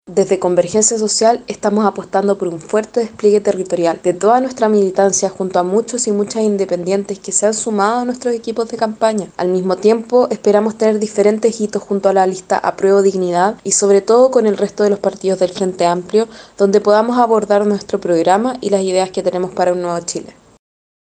La presidenta de Convergencia Social, Alondra Arellano, señaló que se enfocarán en cubrir los territorios con sus candidatas y candidatos, en coordinación con el pacto Apruebo Dignidad, pero además respaldando a independientes.